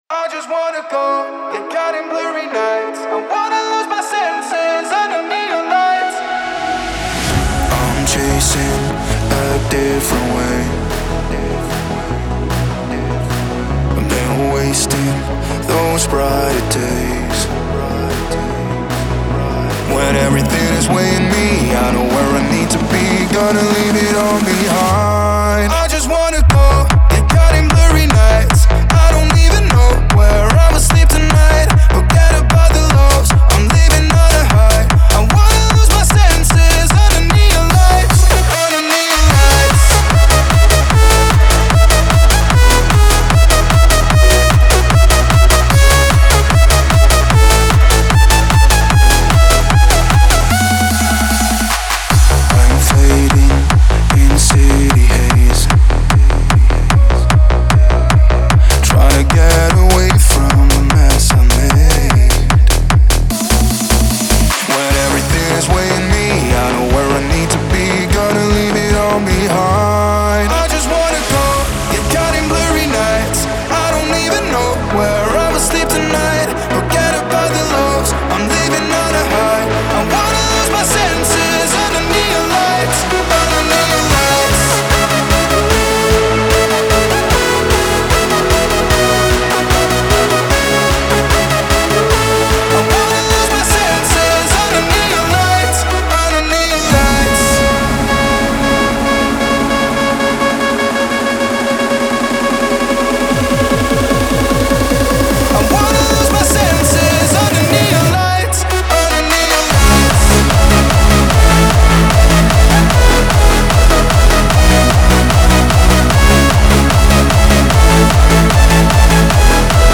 pop , dance